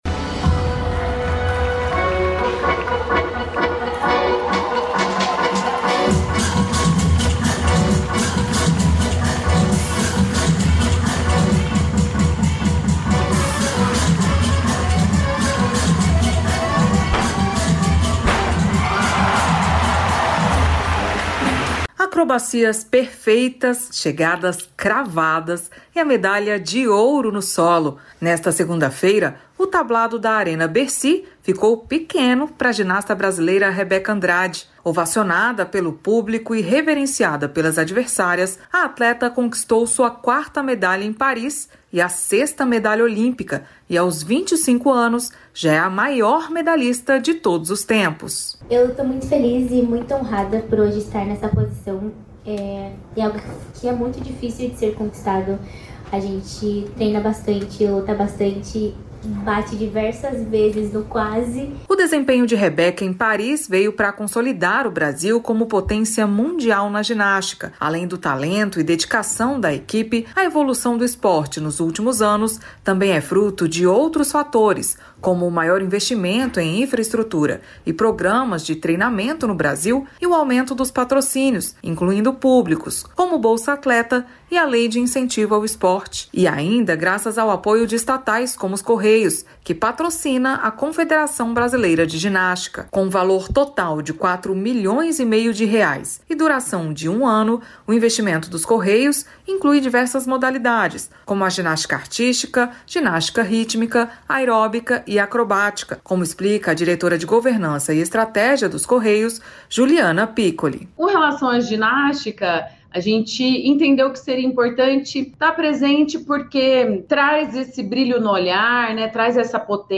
Matérias da Voz